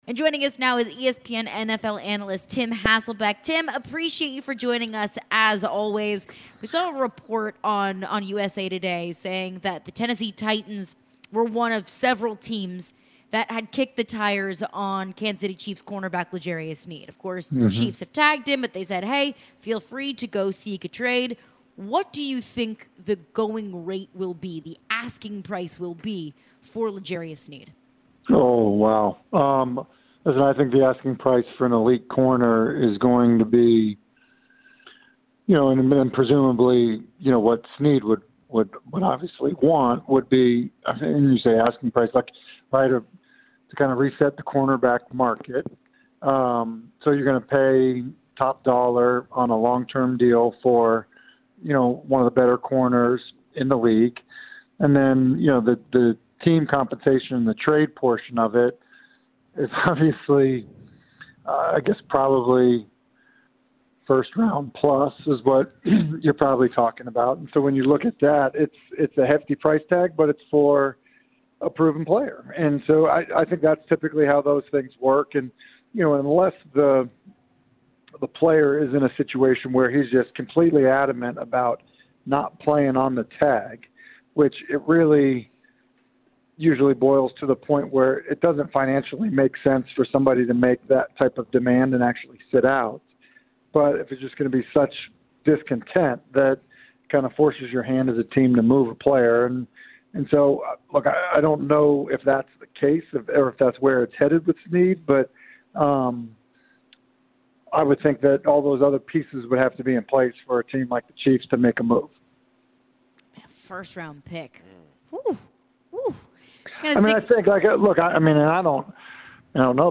guys chat with ESPN NFL analyst Tim Hasselbeck about the Titans and the upcoming draft.